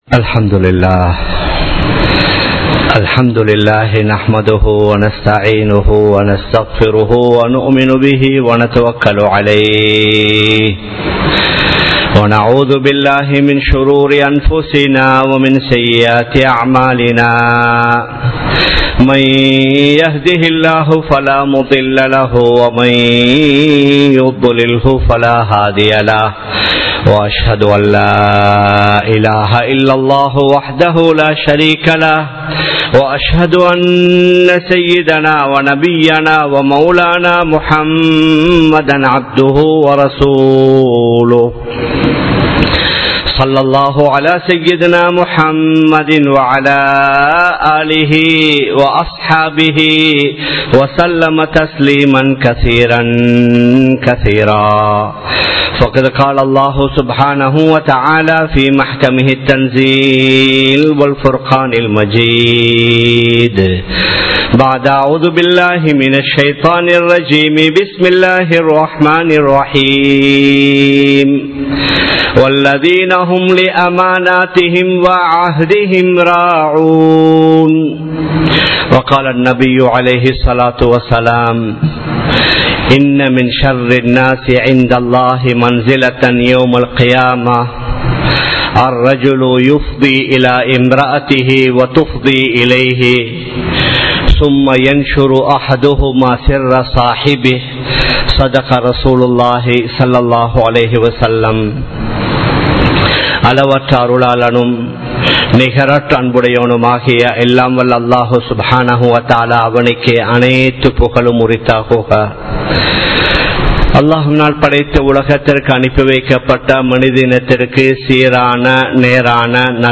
அமானிதம் என்றால் என்ன? | Audio Bayans | All Ceylon Muslim Youth Community | Addalaichenai